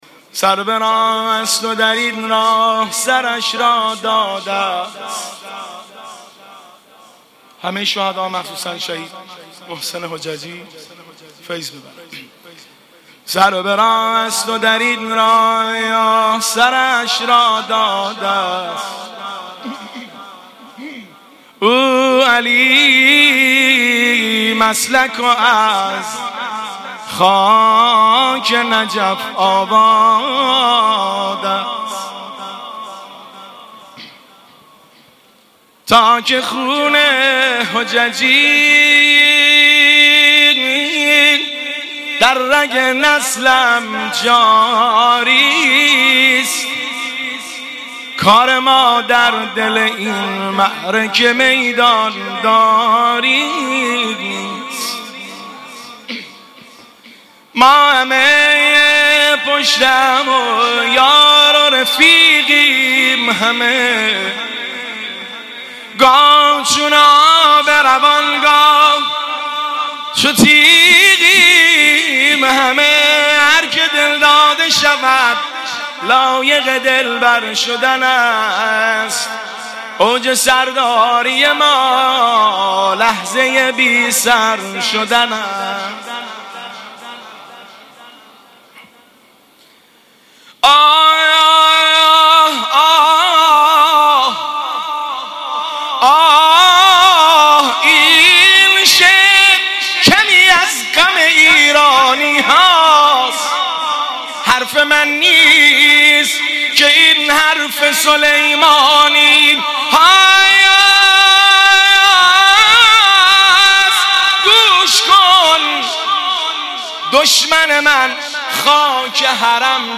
محرم 96 - شعرخوانی - سر به راه است و در این راه